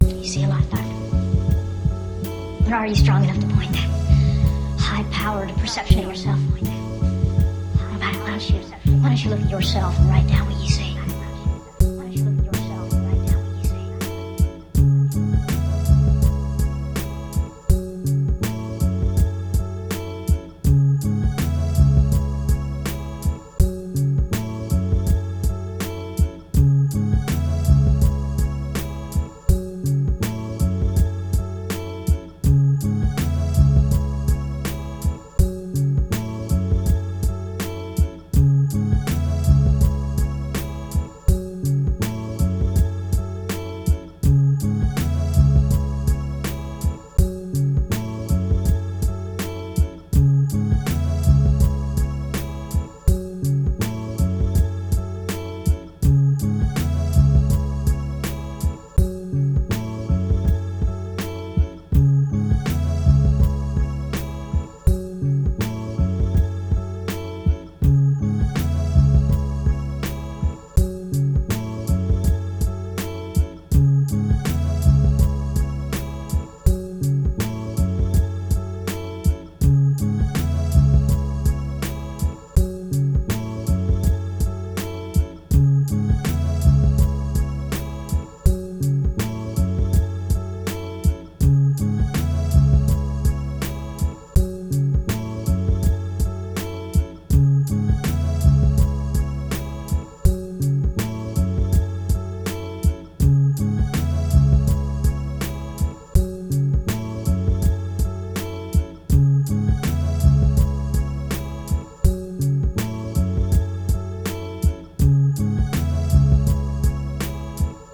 Nice little beat tape
Beats are like cannablism…Eating up your eardrums raw